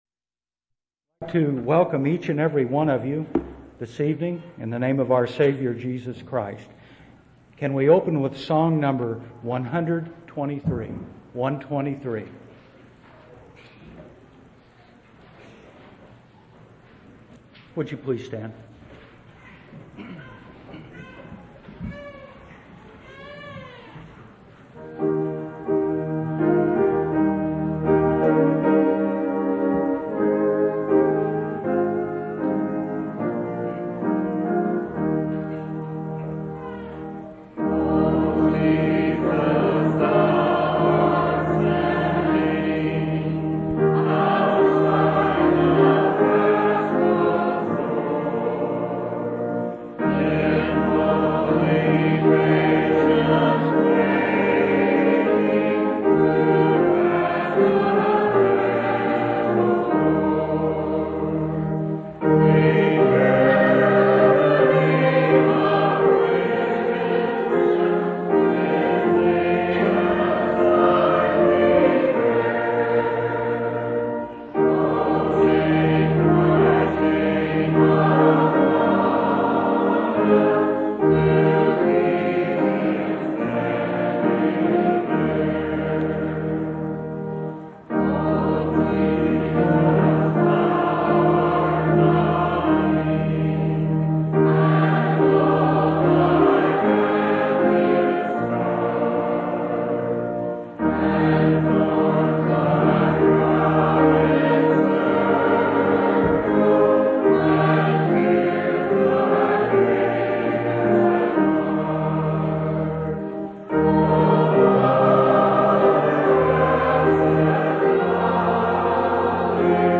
Missouri Reunion Event: Missouri Reunion